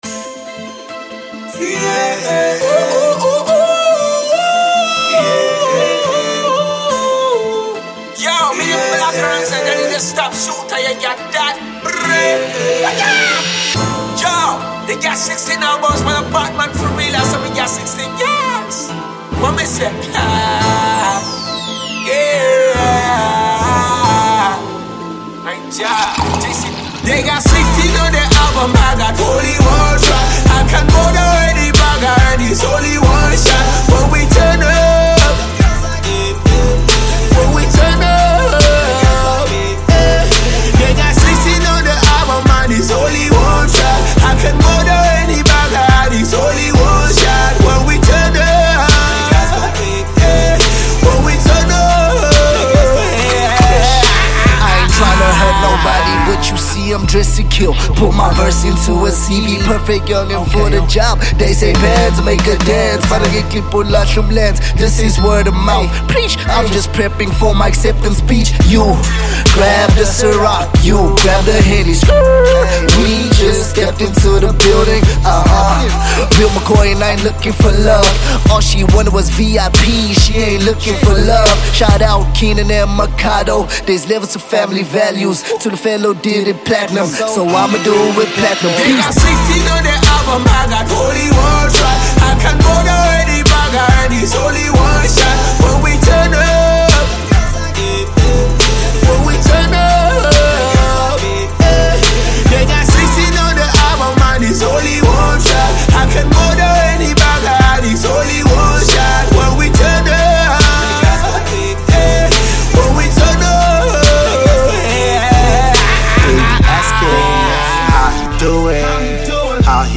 AudioHip-Hop